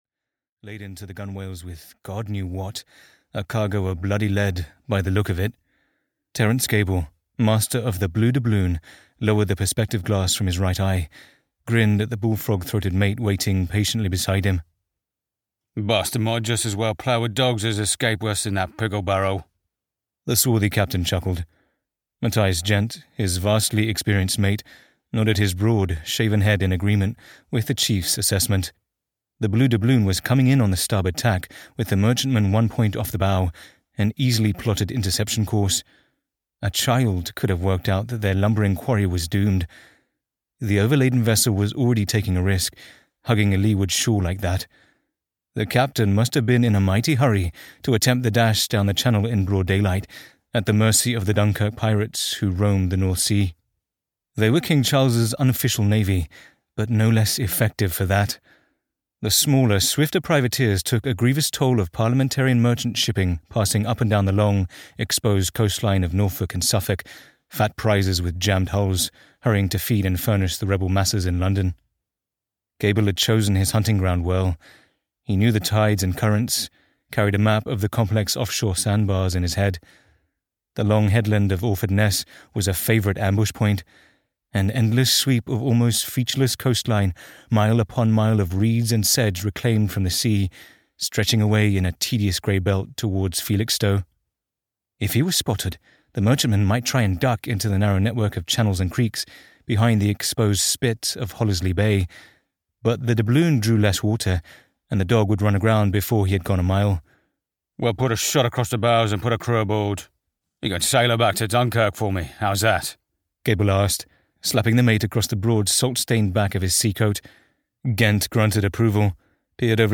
Harvest of Swords (EN) audiokniha
Ukázka z knihy